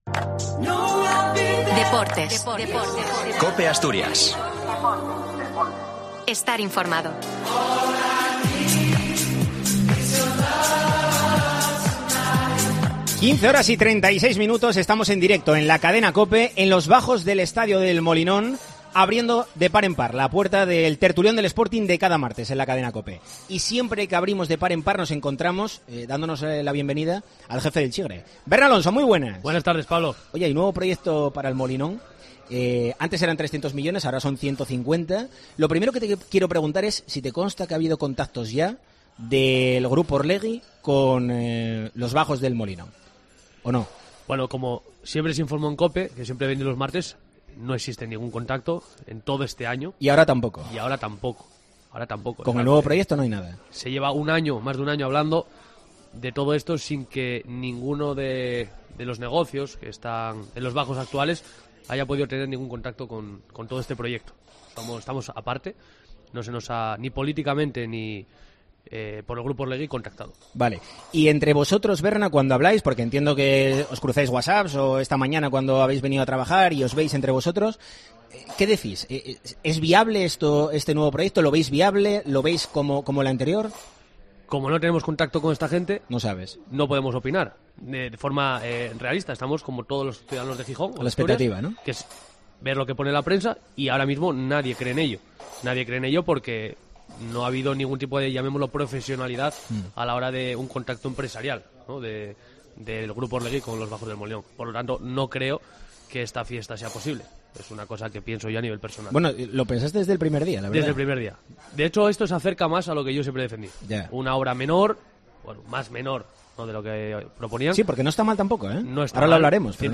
Debatimos acerca del cambio de plan del Sporting sobre El Molinón: de 300 millones a 150 para el Mundial 2030.